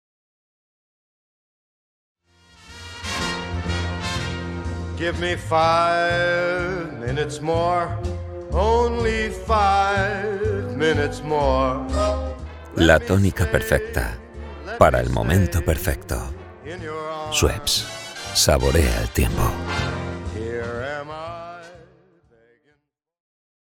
Male
Madrid nativo
Promo Black Friday.mp3
Microphone: Neumann Tlm 103